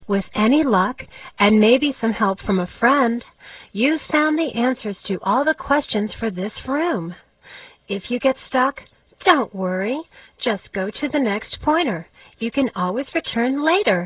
Sound: Listen to Klio (a Muse!) tell you the instructions (14").